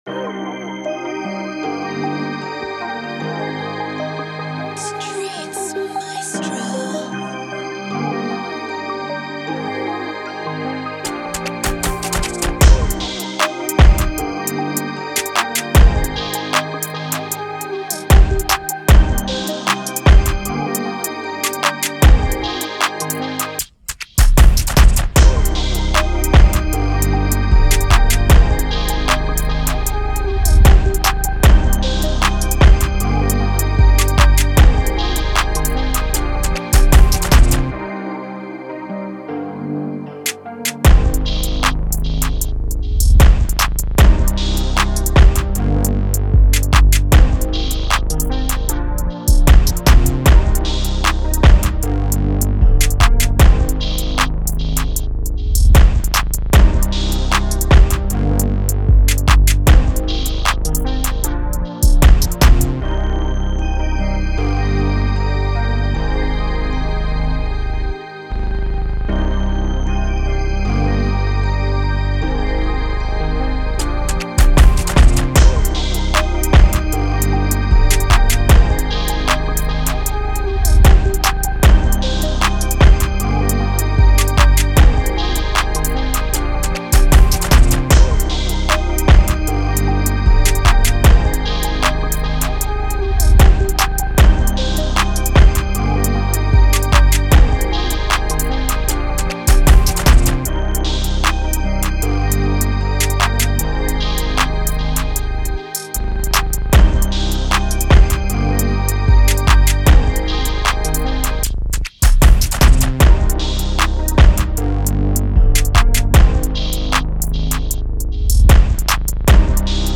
Moods: laid back, intimate, mellow
Genre: Sexy Drill
Tempo: 153
BPM 130